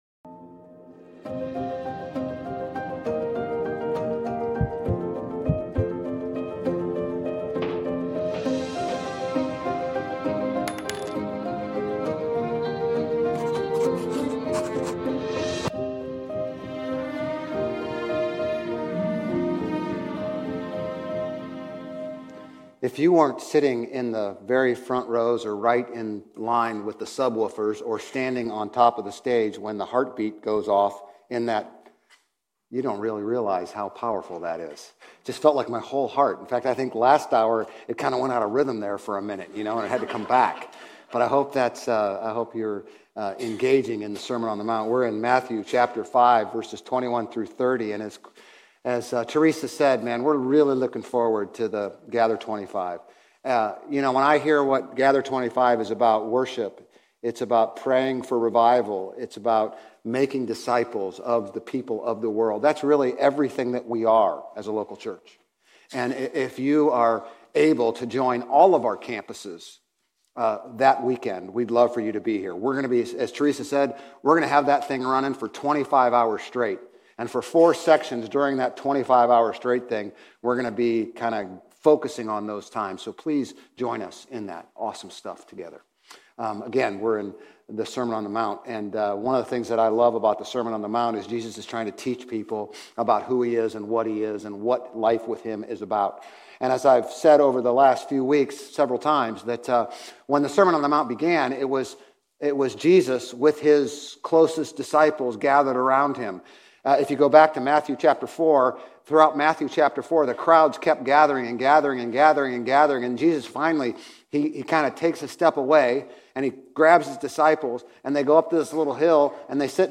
Grace Community Church Old Jacksonville Campus Sermons 2_16 Old Jacksonville Campus Feb 17 2025 | 00:35:00 1x 00:00 / 00:35:00 Subscribe Share RSS Feed Share Link Embed